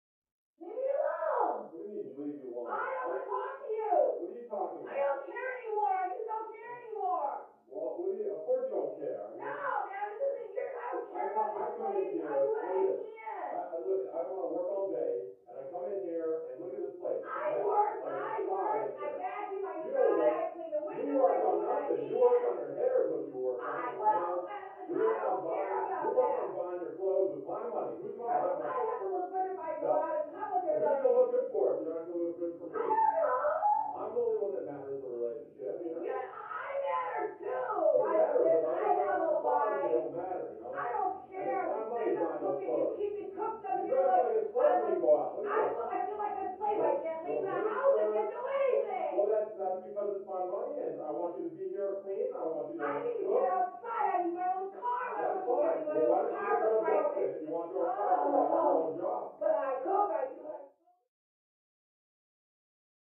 Argue; Couple Yelling At Each Other, From Down Hallway.